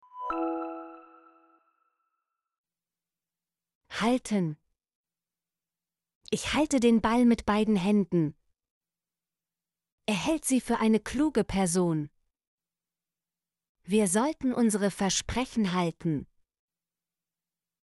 halten - Example Sentences & Pronunciation, German Frequency List